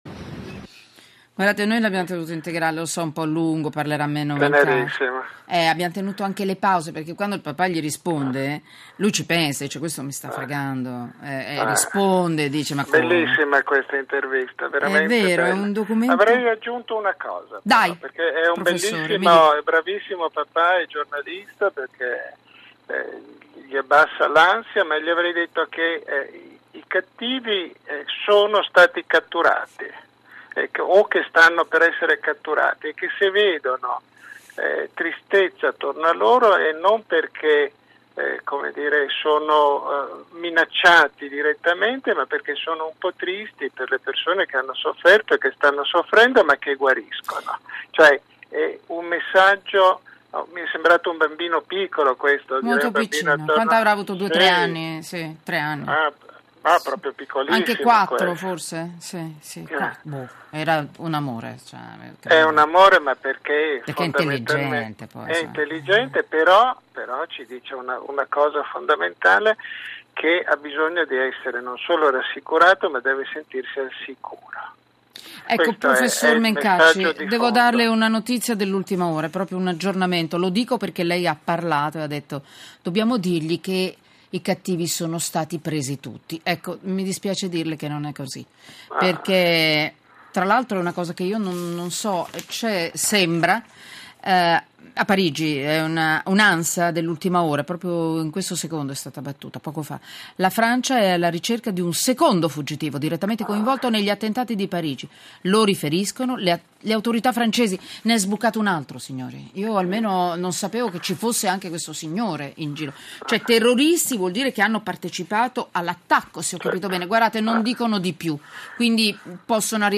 Trasmissione radiofonica di RadioUno Rai